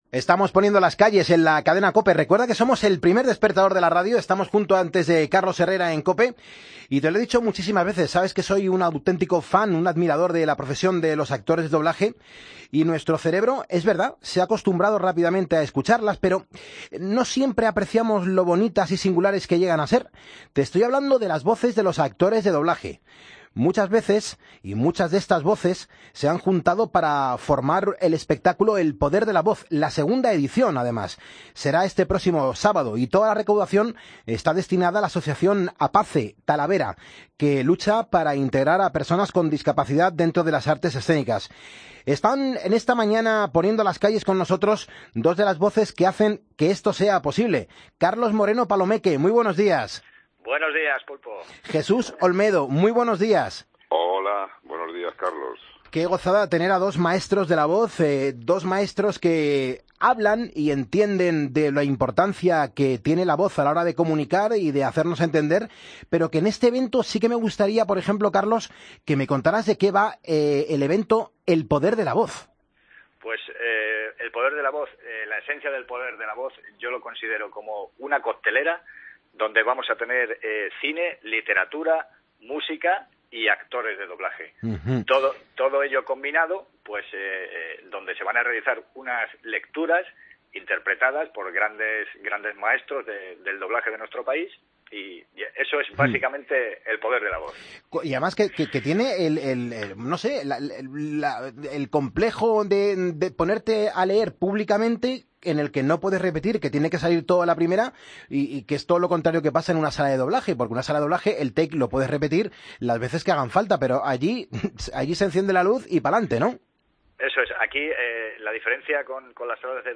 Charlamos con dos de los participantes